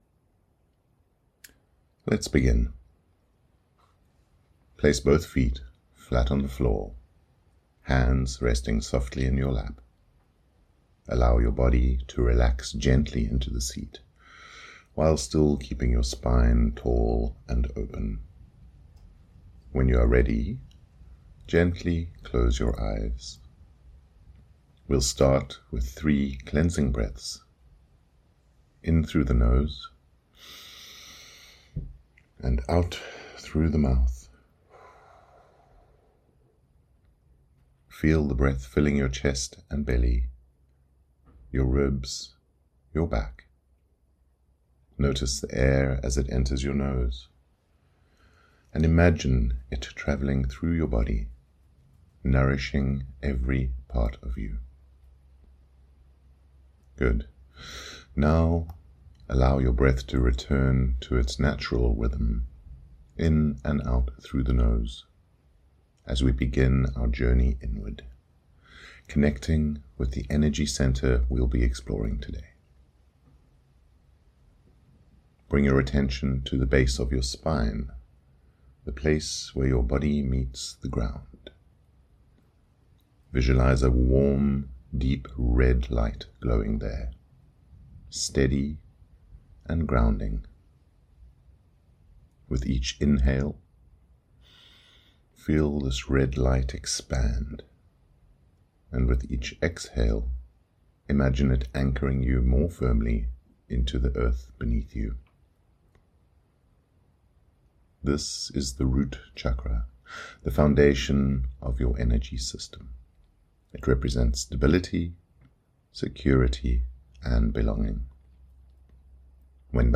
Root Chakra Meditation
CH01-Root-chakra-meditation.mp3